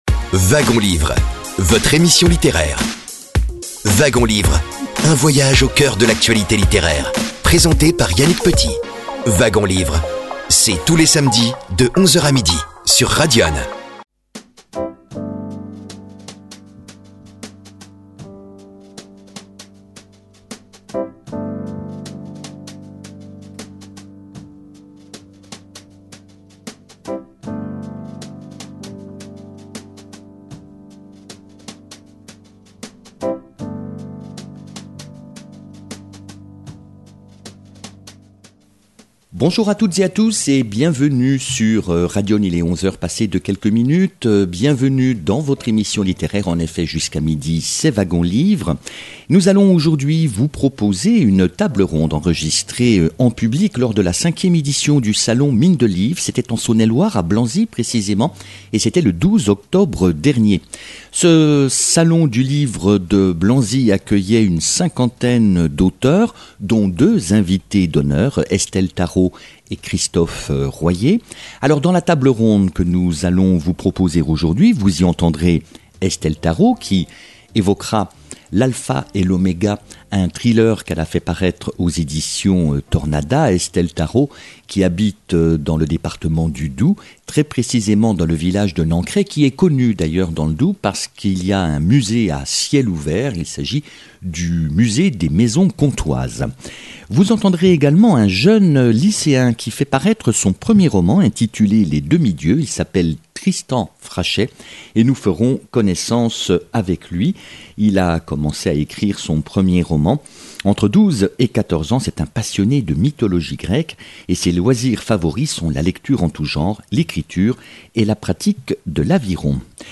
Table ronde n° 2 du Salon du Livre de Blanzy (12 octobre 2025).
Diffusion de la table ronde enregistrée en public lors du salon du livre de Blanzy (71)